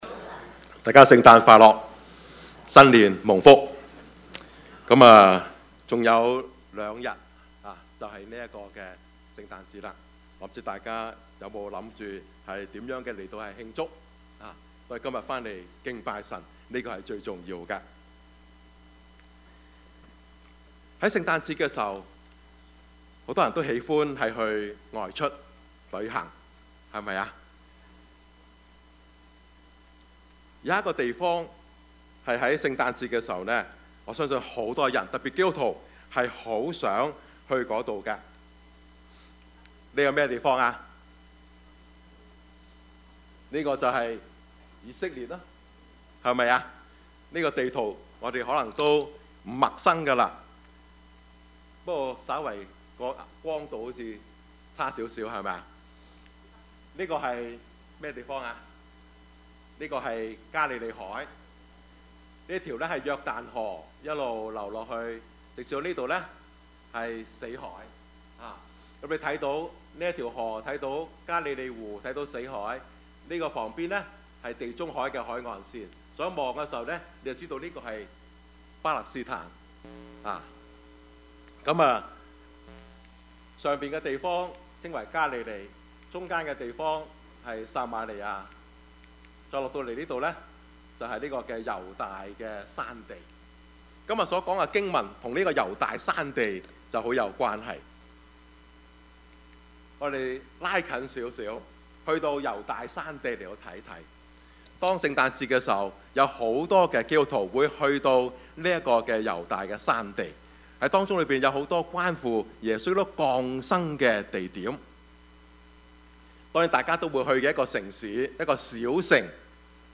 Passage: 路加福音 1:46-55 Service Type: 主日崇拜
Topics: 主日證道 « 生之謎 異鄉客 »